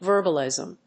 音節vér・bal・ìsm 発音記号・読み方
/‐lìzm(米国英語)/